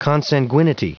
Prononciation du mot consanguinity en anglais (fichier audio)
Prononciation du mot : consanguinity